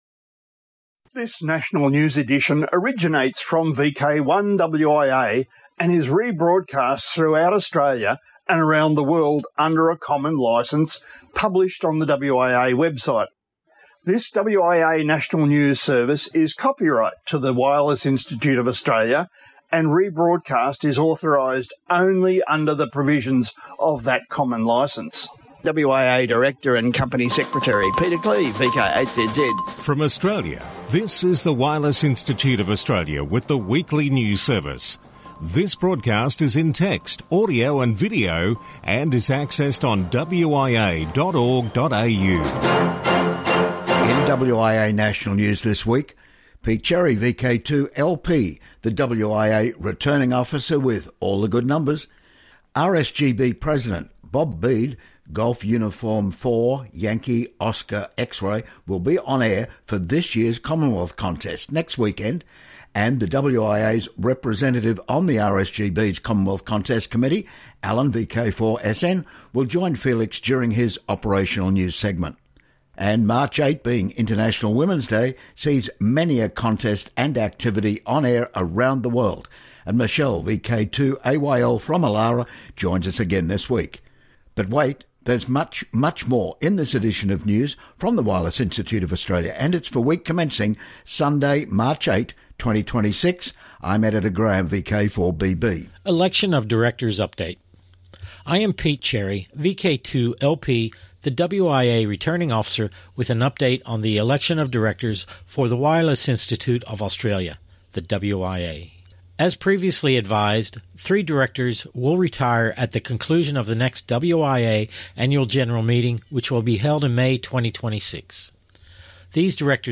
2026 MARCH 8 WIA NATIONAL NEWS BROADCAST ON VK1WIA